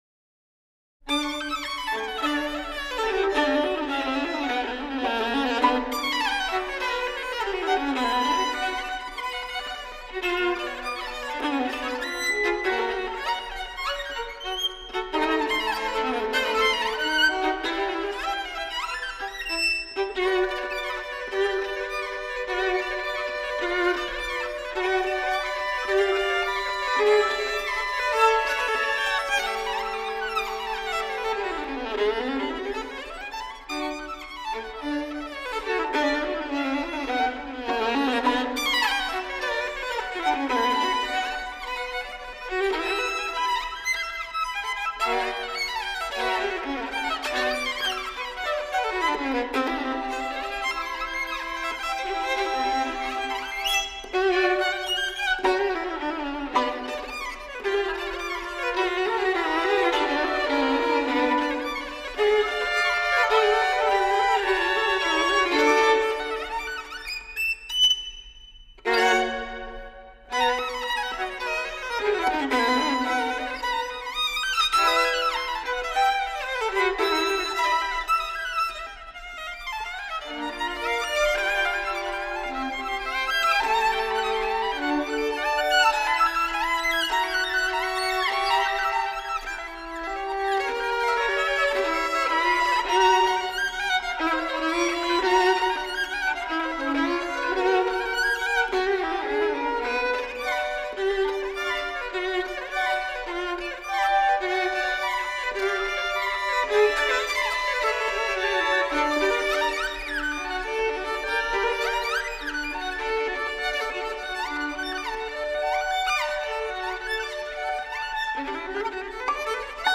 pro dvoje housle